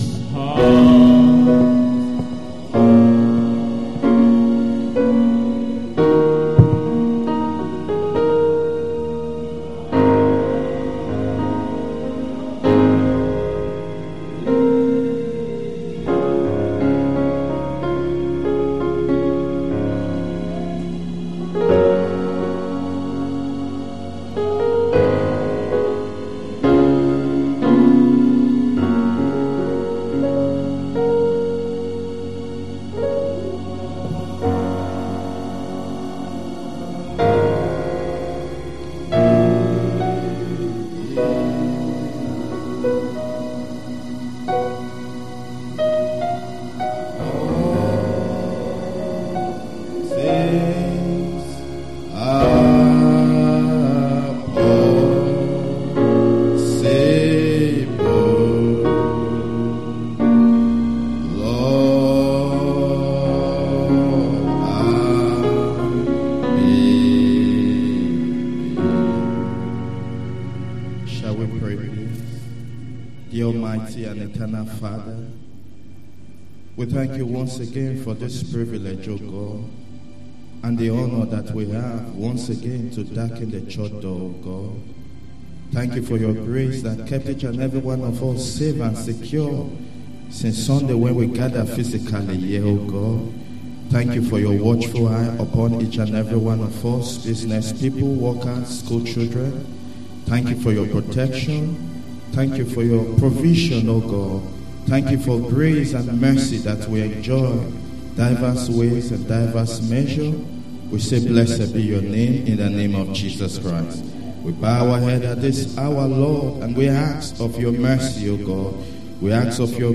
Wed. Prayer Meeting 02/10/24